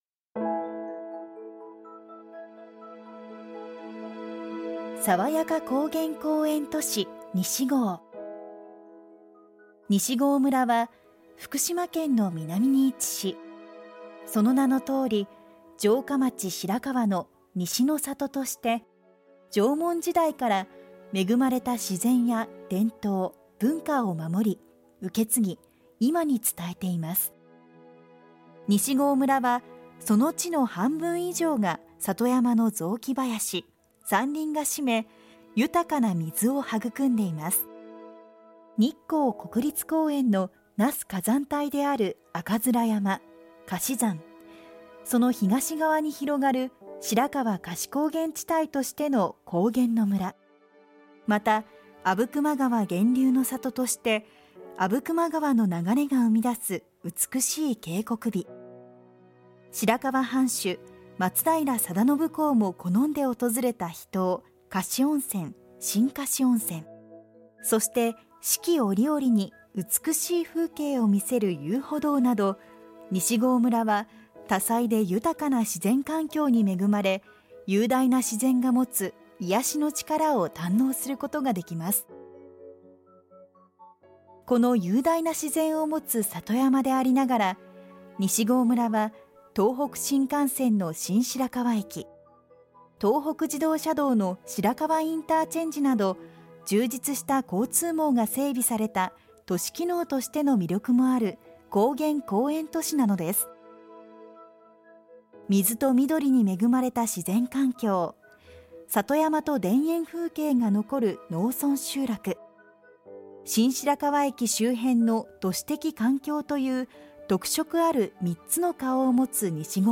西郷村テレホンガイド